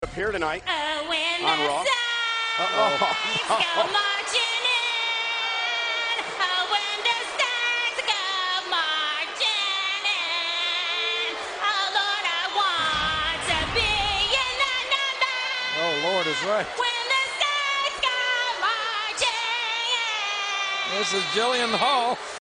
Prior to the match however, we got even more entertainment, as she gave us her rendition of “
When the Saints Come Marching In“. A toe tapper to be sure – watch closely and you can see even Kelly is enjoying it!
kellykelly-jillianhall-01.mp3